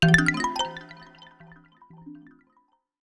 xilophone_rare_2.wav